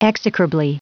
Prononciation du mot execrably en anglais (fichier audio)
Prononciation du mot : execrably
execrably.wav